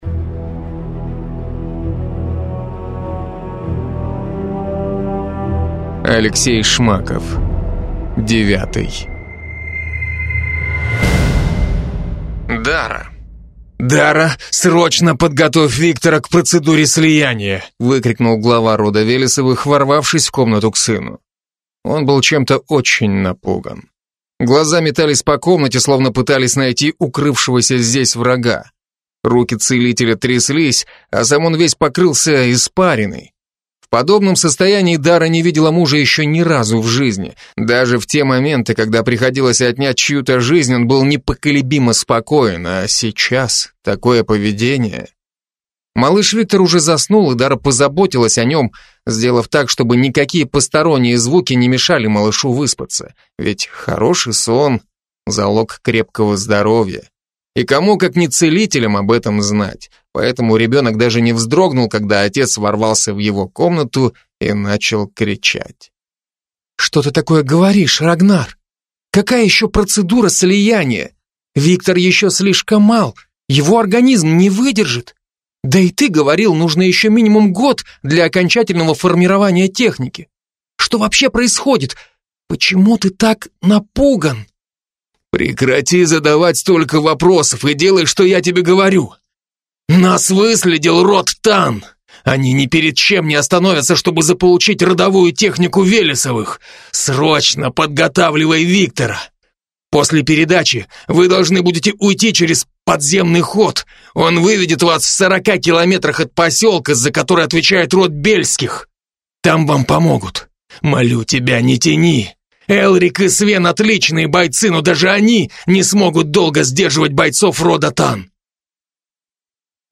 Аудиокнига Девятый | Библиотека аудиокниг